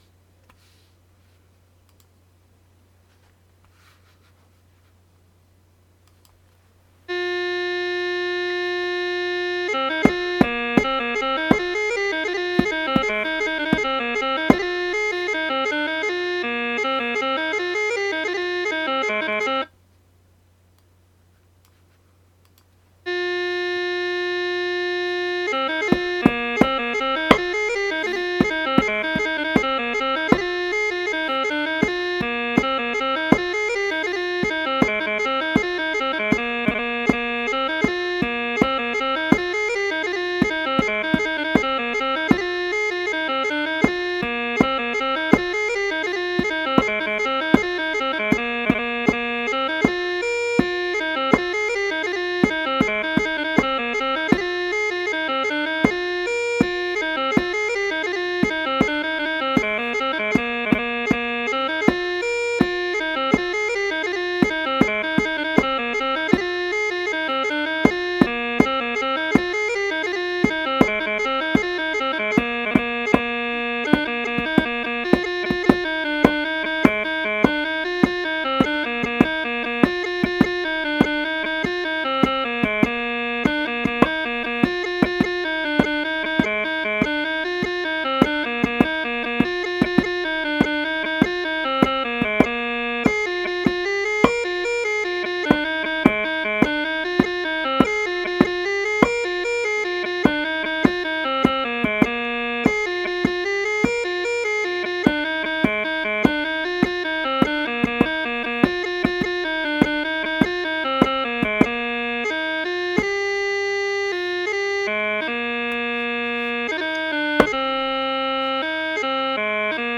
Tunes are based on a march tempo of 74bpm
Bass
DAMMJ4 WS v0b Chanter+Bass.mp3